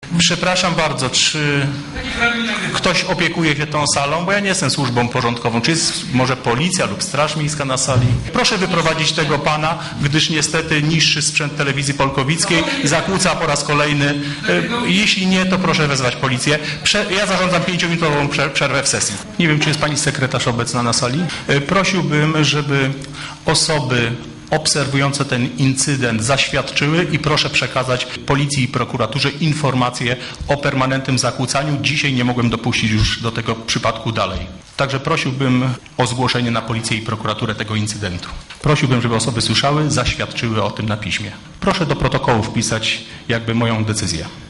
Nagranie TV Polkowice: